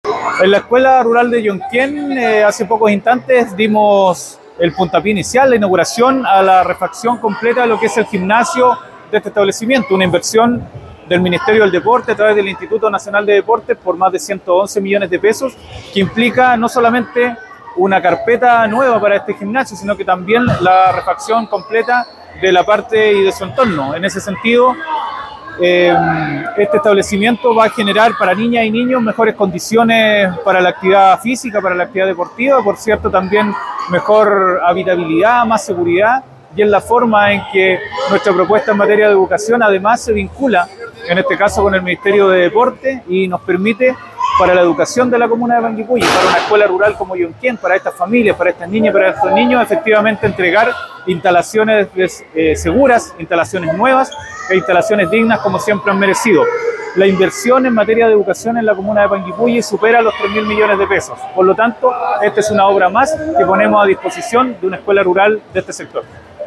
Llonquen-Alcalde-de-Panguipulli-.mp3